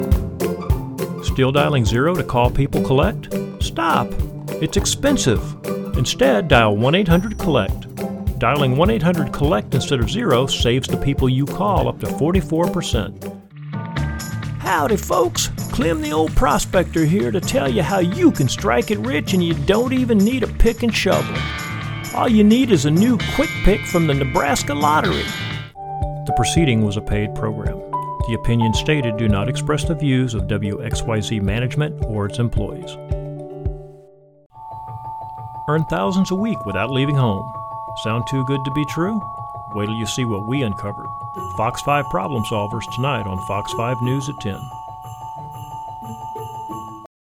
Originally from Texas, I have a faint accent that can be amped up when needed.
Let me bring the energy and enthusiasm to your project.